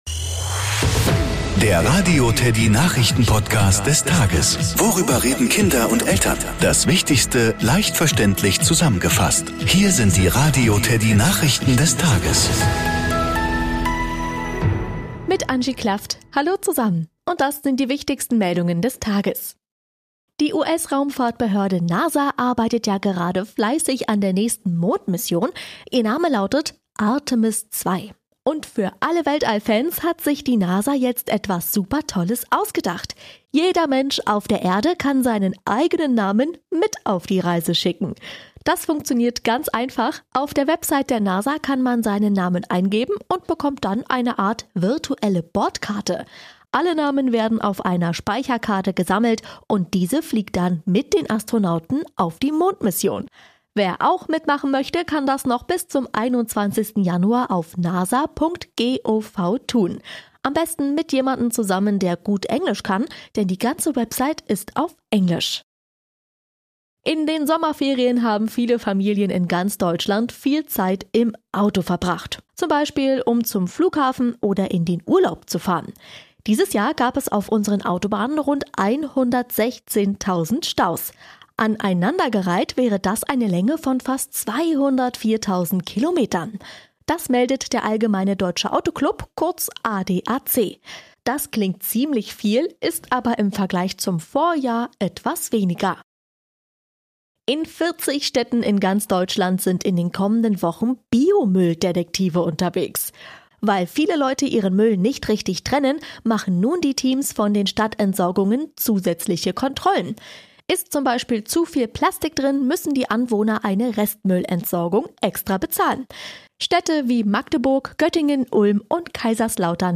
Nachrichten , Kinder & Familie